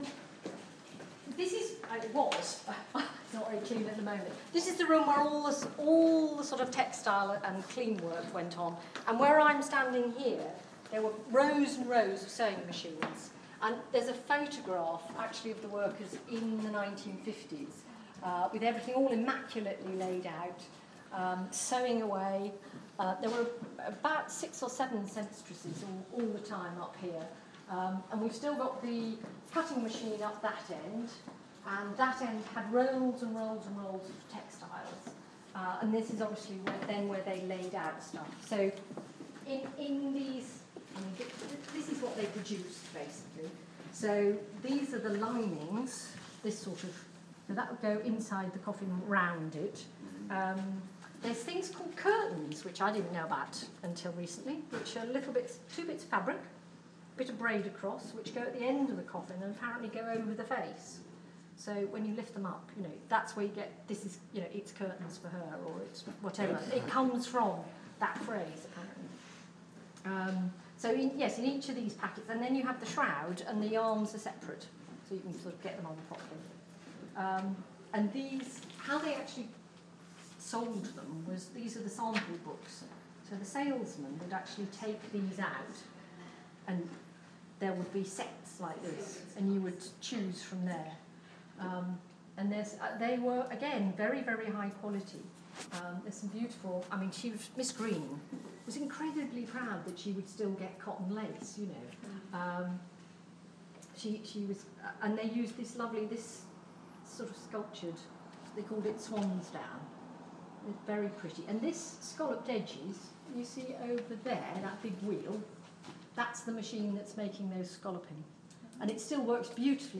"curtains for you" Heritage open day Newman Brothers